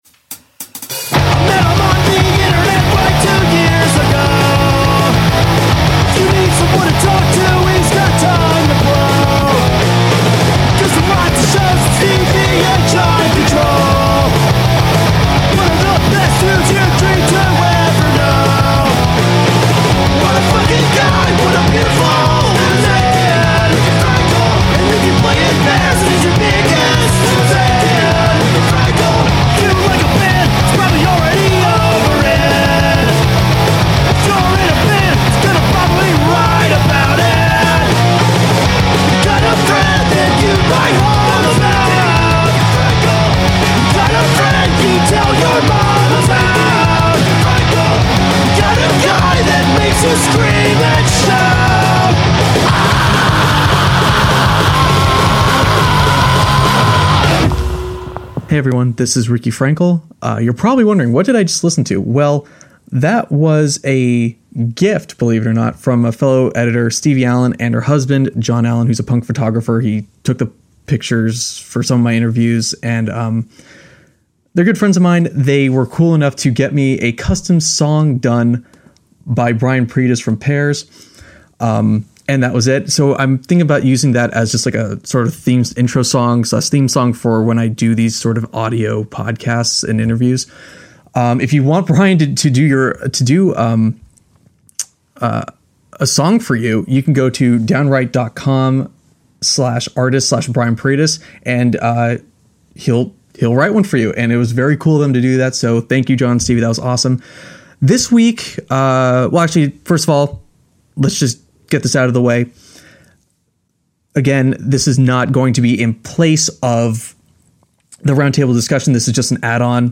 Bonus - Interview with The Bombpops